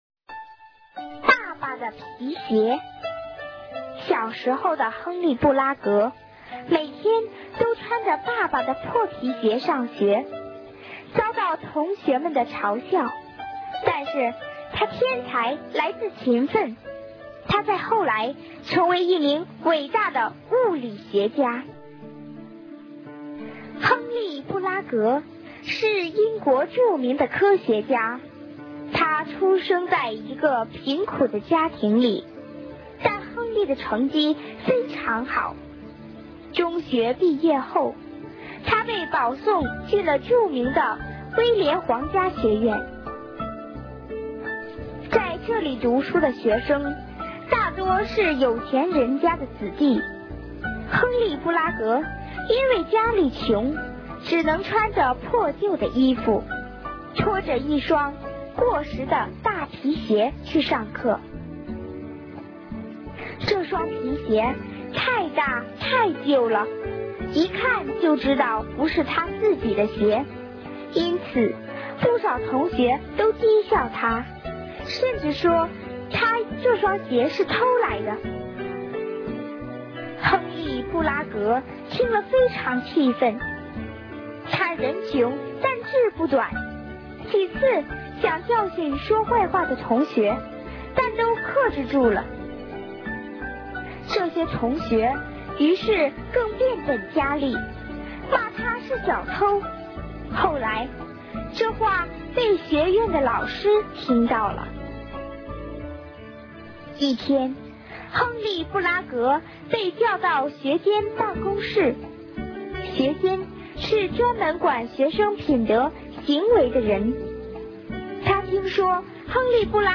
首页>mp3 > 儿童故事 > 爸爸的皮鞋